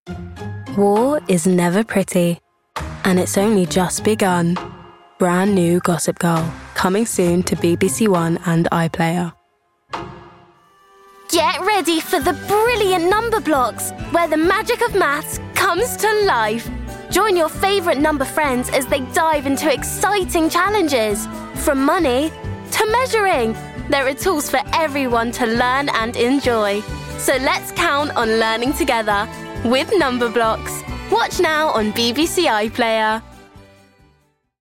A young, fresh & cool voice with lots of energy and confidence.